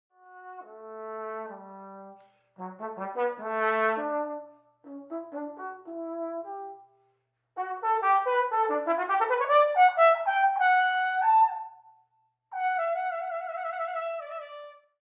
Звуки тромбона
Тромбонят